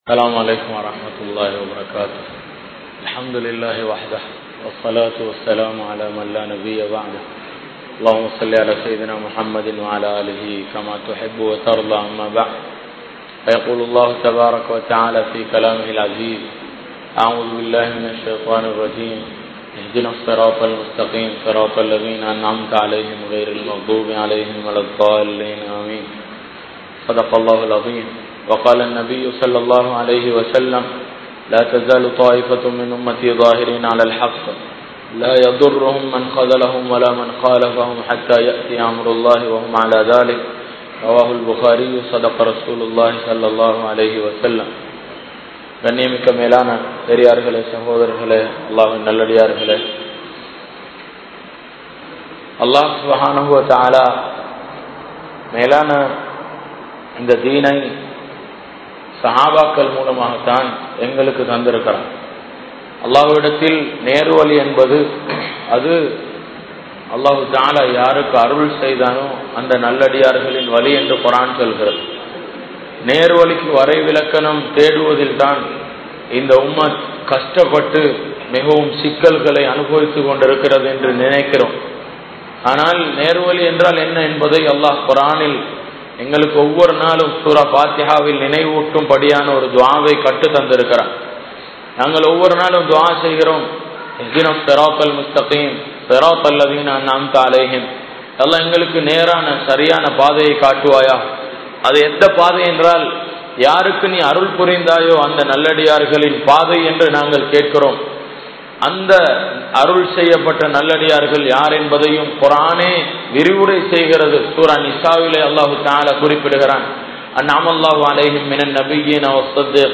Shahabaakkal Enpoar Yaar? (ஸஹாபாக்கள் என்போர் யார்?) | Audio Bayans | All Ceylon Muslim Youth Community | Addalaichenai
SLBC, Programme